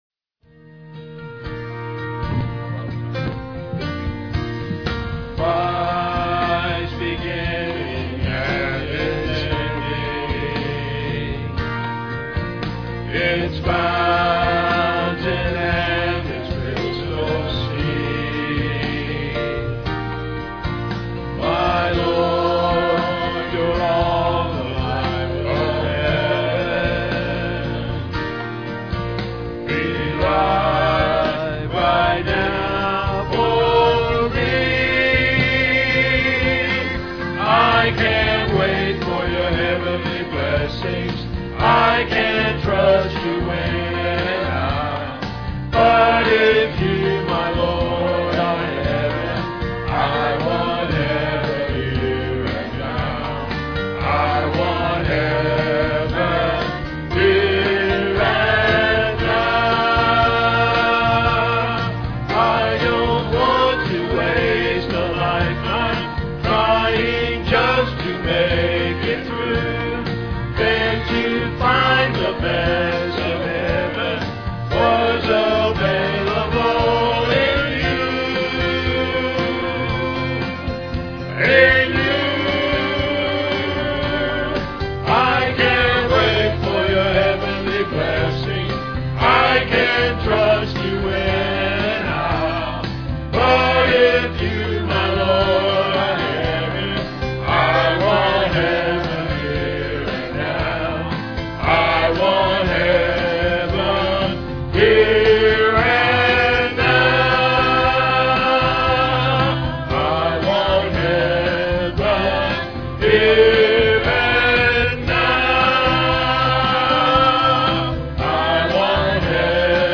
PLAY Savior's Last Will and Testament, Apr 15, 2007 Scripture: Mark 16:14-20. Scripture reading